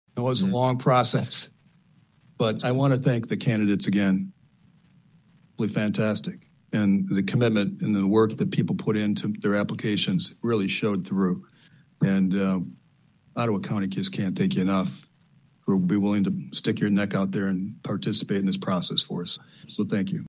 Once the vote was taken, current board chairman and District 7 Commissioner John Teeples of Jenison was appreciative of the process.